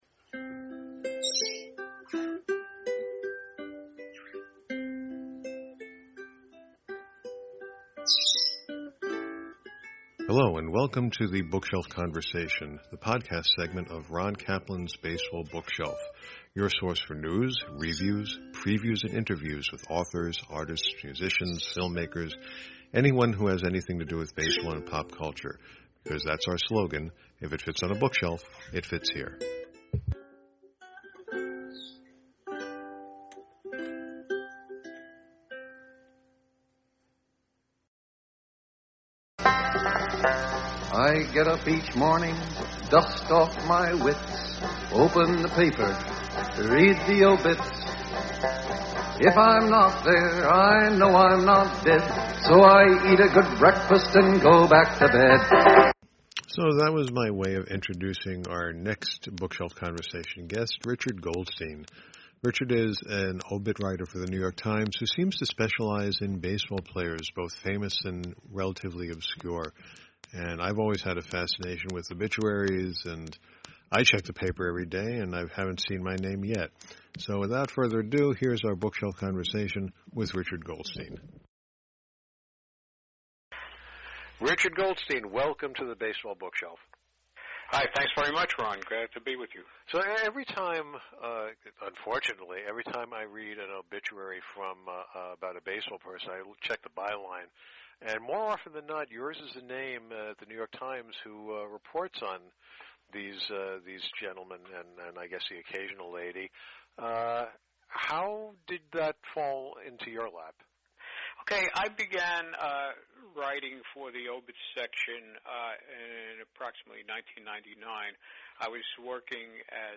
The Bookshelf Conversation